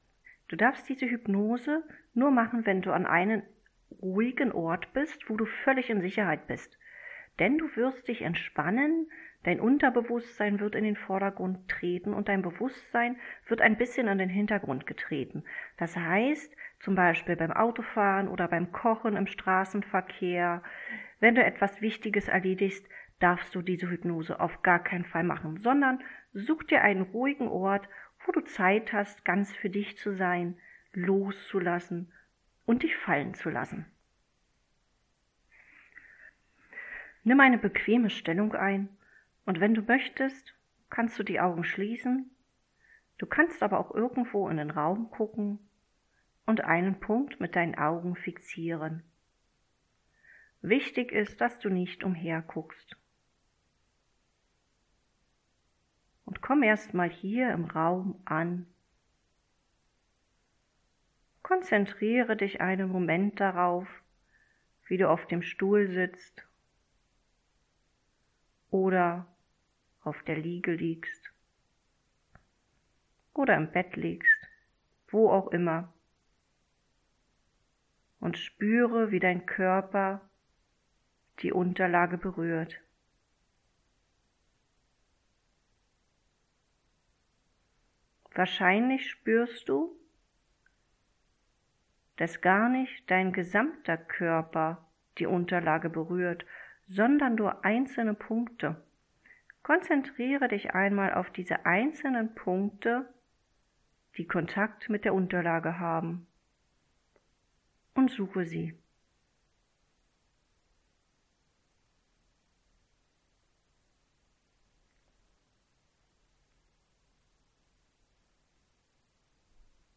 Wohlfuehlort_hypnose.mp3